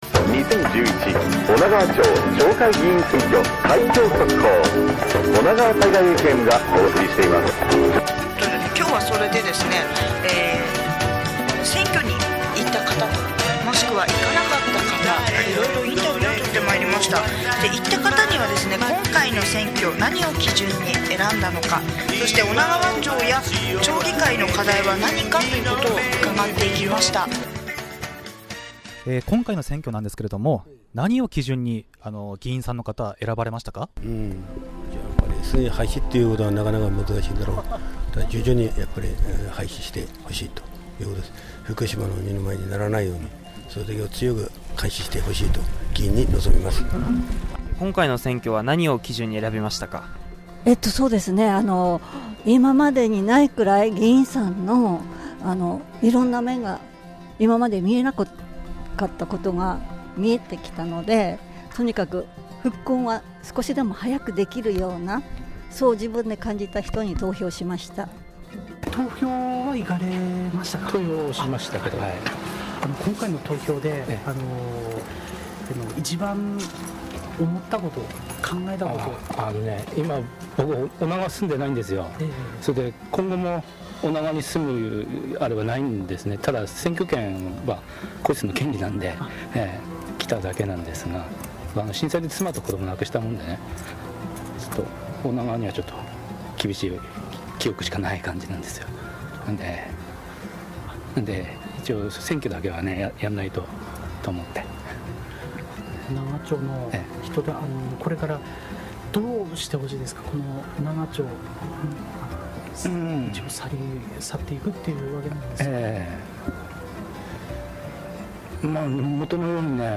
おながわさいがいＦＭでは町選挙管理委員会の要請を受け、 夕方６時から４時間の生放送特番で開票速報を放送しました。
当日は開票所となった女川町総合体育館とスタジオを二元中継し、 随時開票状況をお伝えした他、